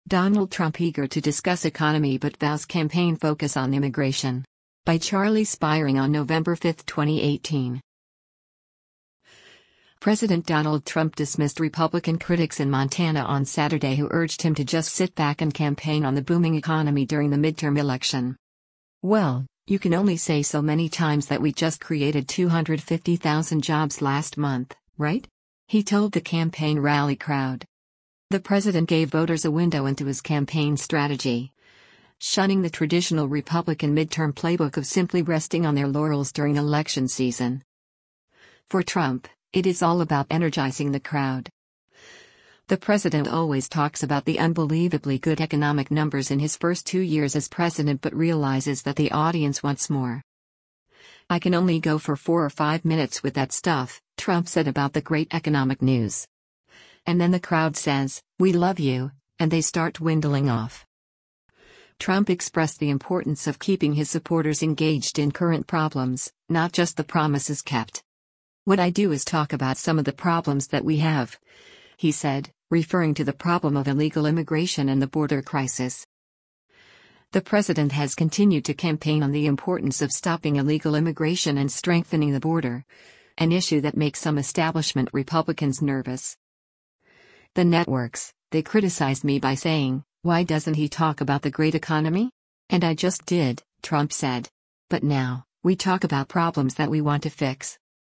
“Well, you can only say so many times that we just created 250,000 jobs last month, right?” he told the campaign rally crowd.